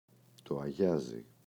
αγιάζι, το [aꞋʝazi]